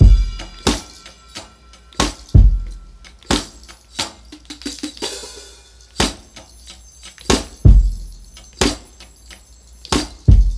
Bella Break.wav